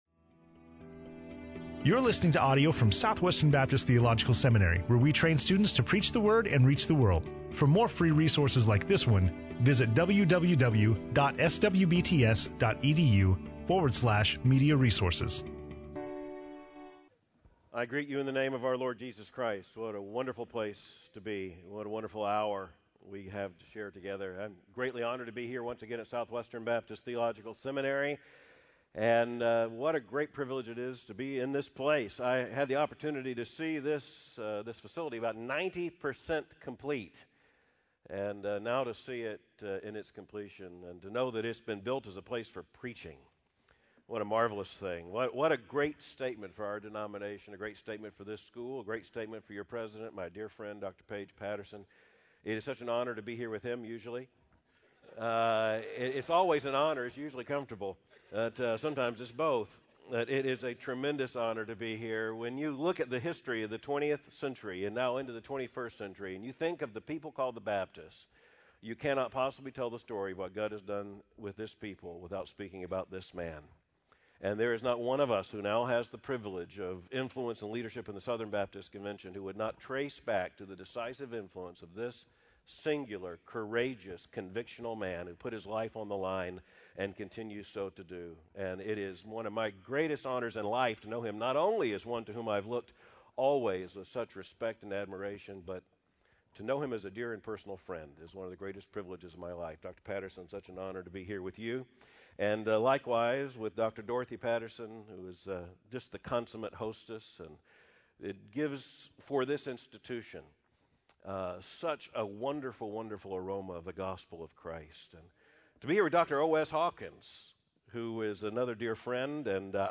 Dr. Albert Mohler speaking on John 9 in SWBTS Chapel on Thursday February 16, 2012
SWBTS Chapel Sermons Albert Mohler - SWBTS Chapel - February 16, 2012 Play Episode Pause Episode Mute/Unmute Episode Rewind 10 Seconds 1x Fast Forward 30 seconds 00:00 / Subscribe Share RSS Feed Share Link Embed